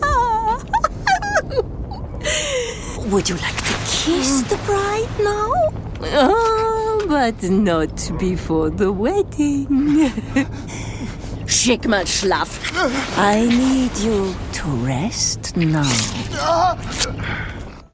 Eastern European ● European Adult
Videogame